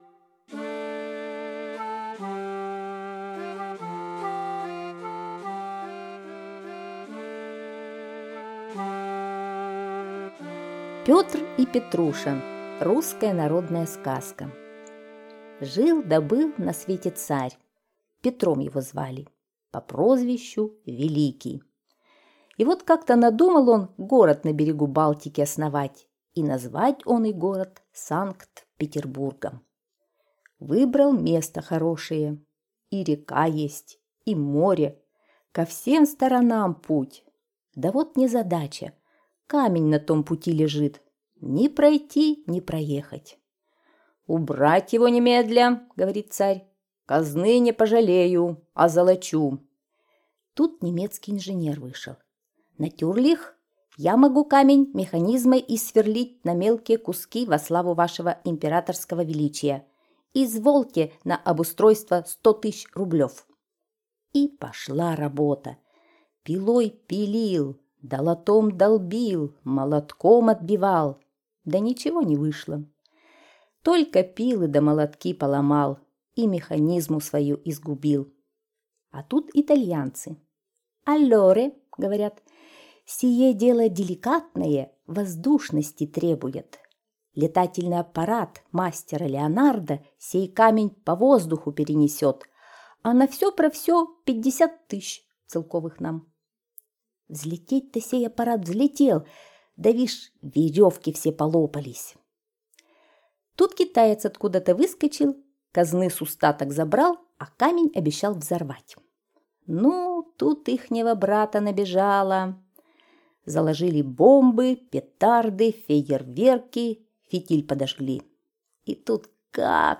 Петр и Петруша - русская народная аудиосказка - слушать онлайн